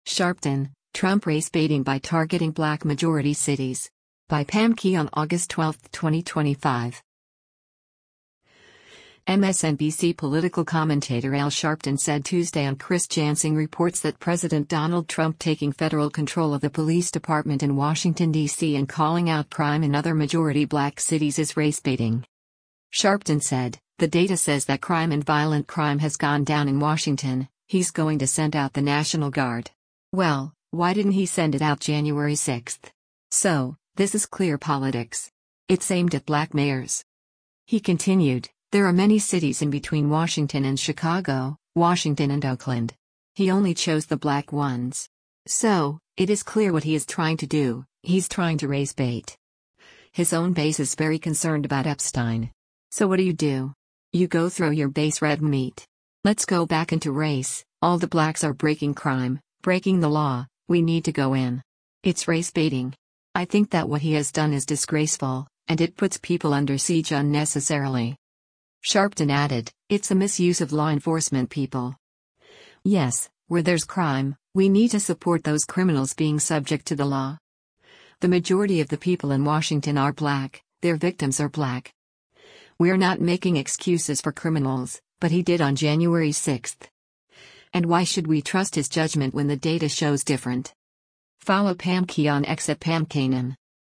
MSNBC political commentator Al Sharpton said Tuesday on “Chris Jansing Reports” that President Donald Trump taking federal control of the police department in Washington, D.C. and calling out crime in other majority black cities is “race-baiting.”